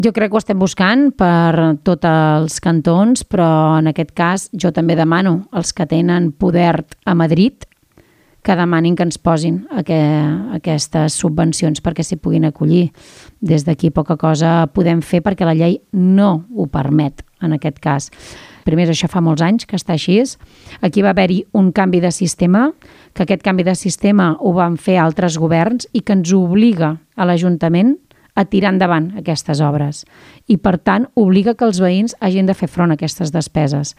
Montse Capdevila també s'ha referit durant l'entrevista a la dificultat de trobar suport econòmic per ajudar els veïns de Can Raimí a pagar les quotes de la urbanització. Assegura que el govern es veu obligat a tirar endavant la primera part que només s'ocupa de canalitzacions d'aigües i de clavegueram.